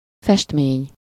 Ääntäminen
IPA: /i.maʒ/